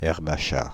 Ääntäminen
France (Île-de-France): IPA: /ɛʁ.b‿a ʃa/